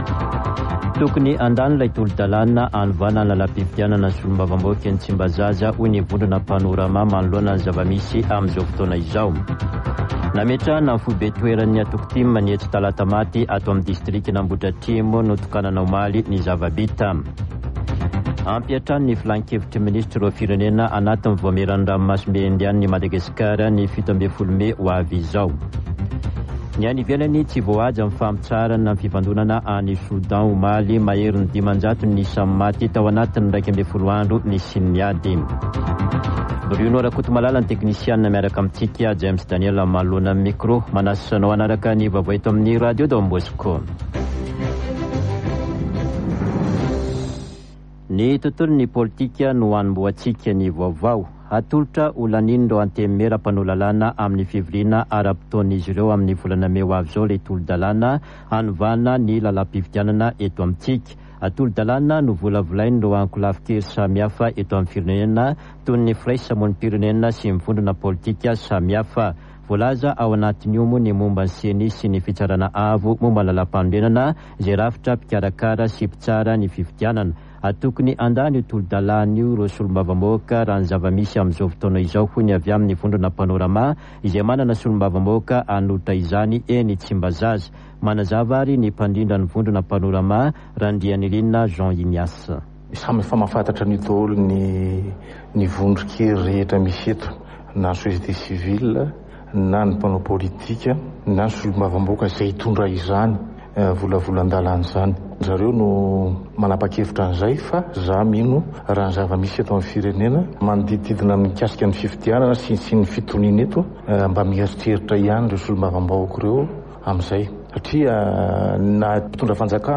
[Vaovao maraina] Alakamisy 27 avrily 2023